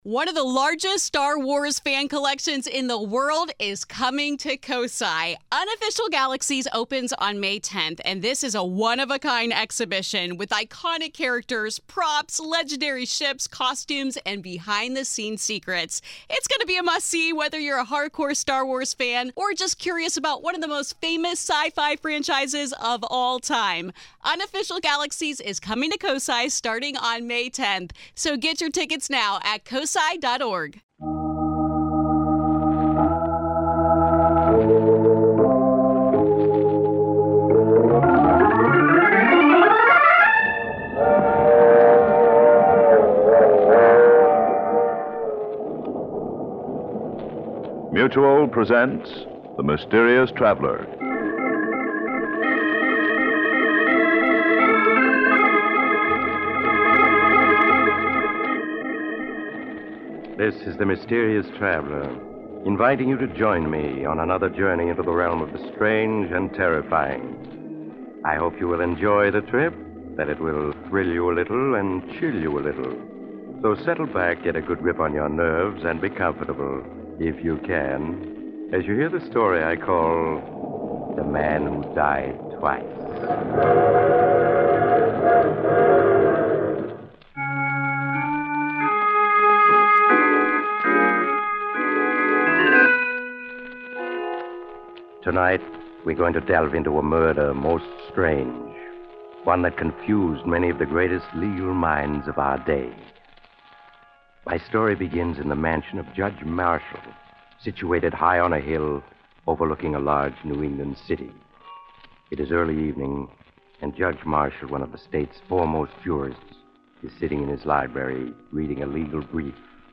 On this episode of the Old Time Radiocast we present you with a double feature of the classic radio program The Mysterious Traveler